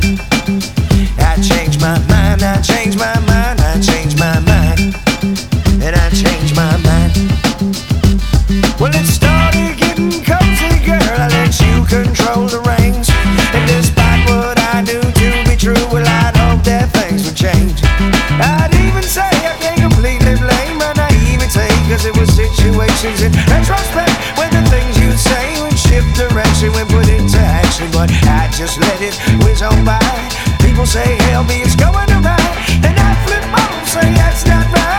West Coast Rap Hip-Hop Rap Underground Rap Alternative Rap
Жанр: Хип-Хоп / Рэп / Альтернатива